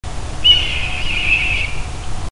Poiana codarossa (Buteo jamaicensis)
redtail.mp3